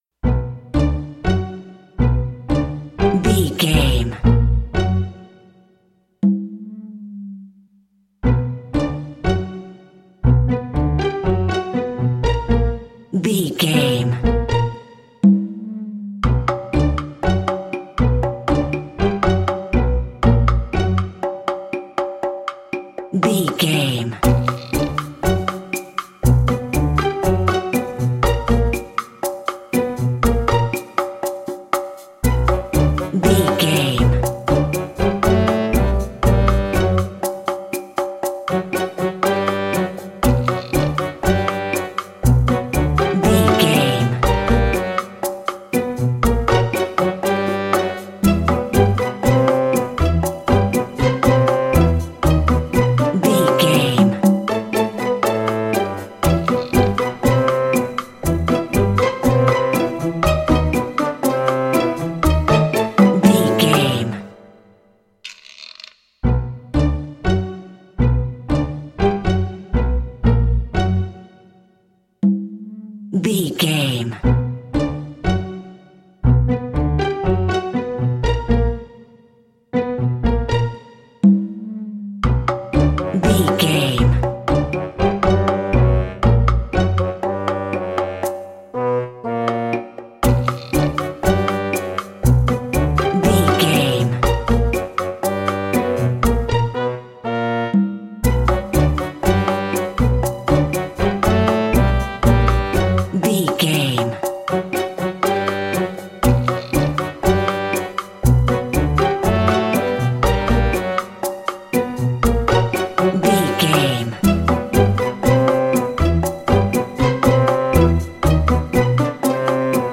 Uplifting
Aeolian/Minor
flute
oboe
strings
cello
double bass
accordion
conga
circus
goofy
comical
cheerful
perky
Light hearted
quirky